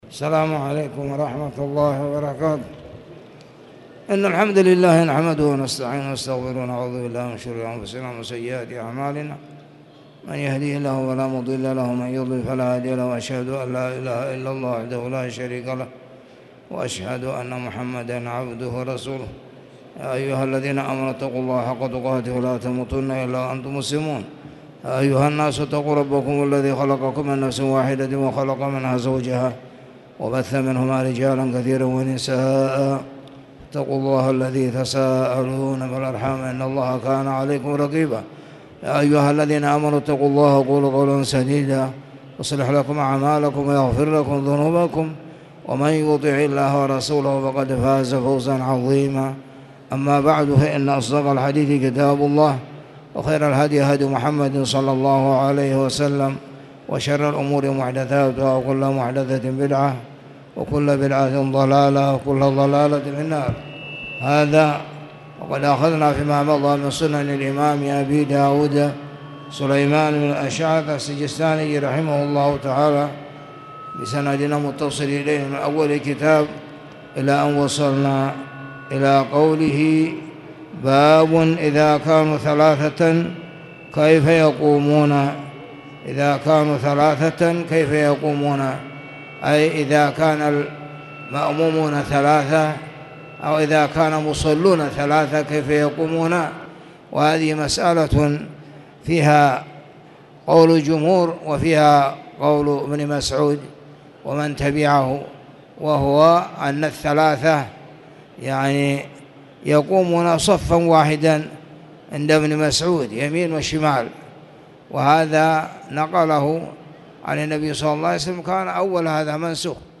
تاريخ النشر ١٠ محرم ١٤٣٨ هـ المكان: المسجد الحرام الشيخ